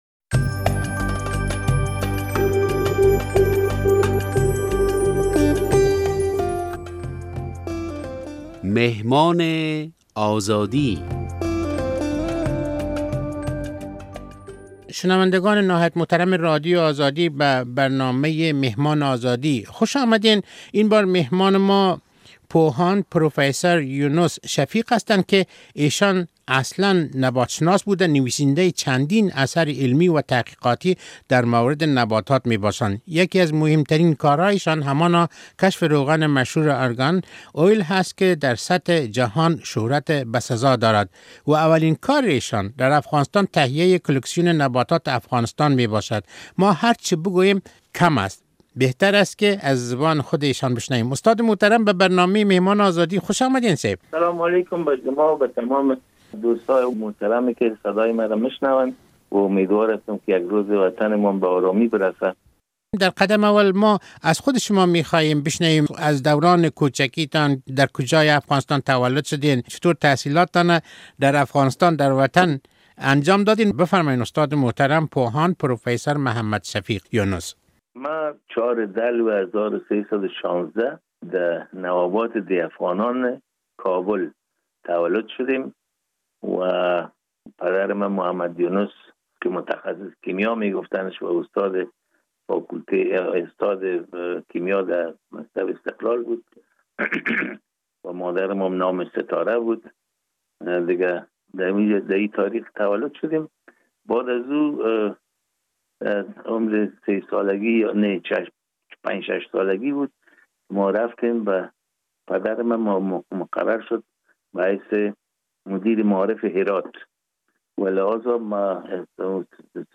مصاحبه‌ای